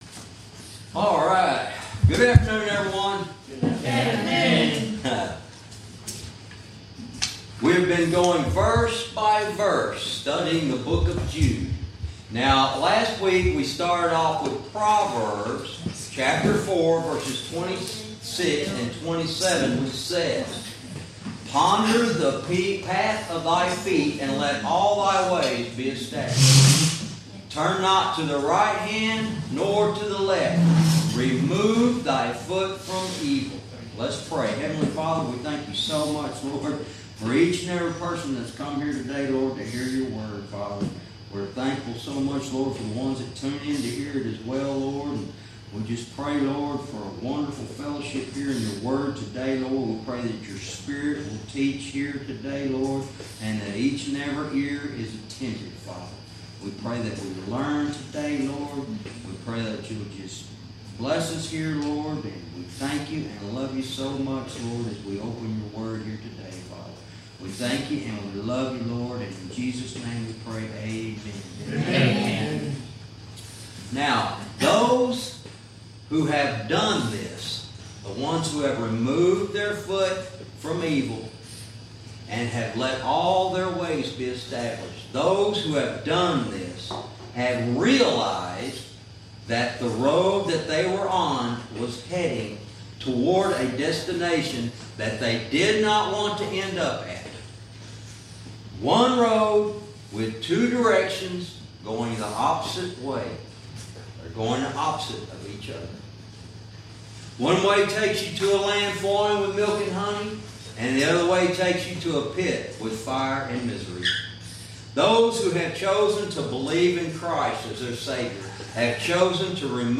Verse by verse teaching - Jude lesson 75 verse 17